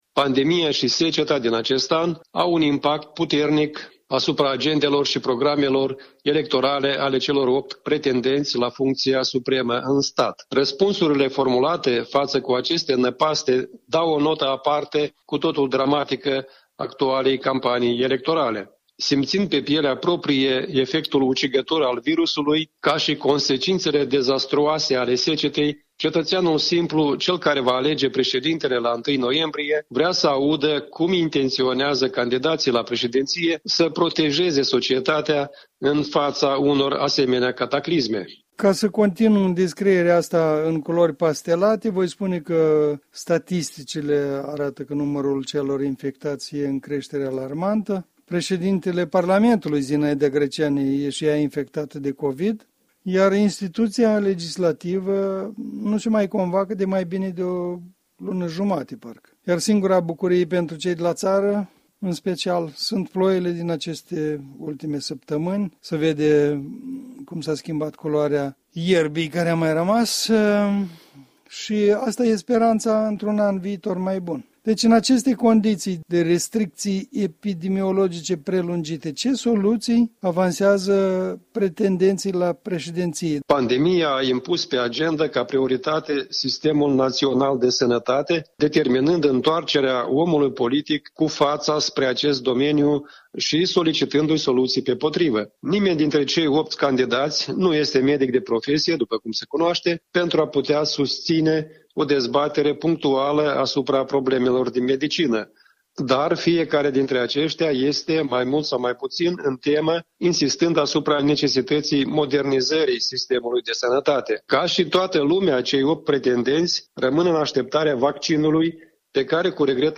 stă de vorbă cu analistul politic